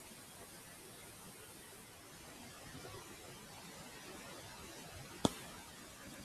after-silence.wav